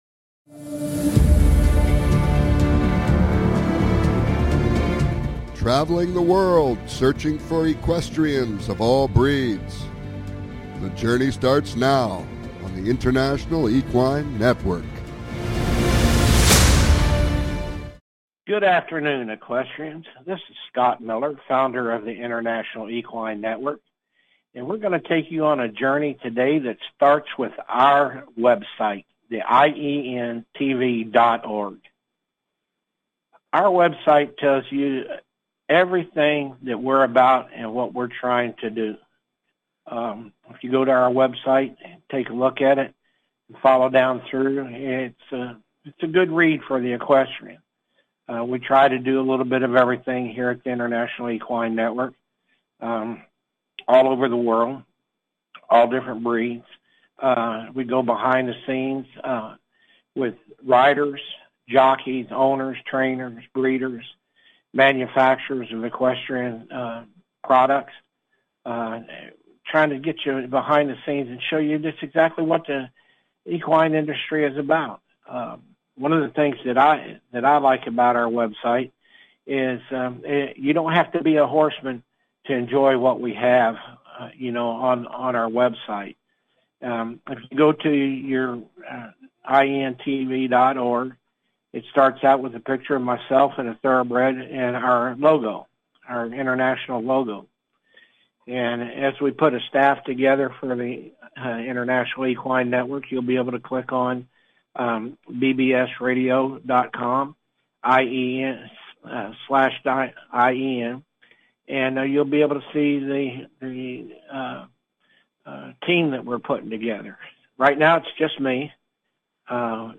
Calls-ins are encouraged!